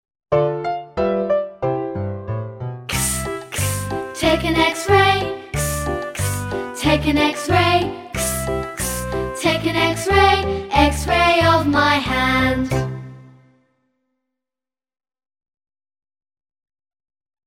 每个发音都配有一个用著名曲调填词的短歌和动作图示。书中也有一些简单的游戏和活动，用以帮助孩子认识发音。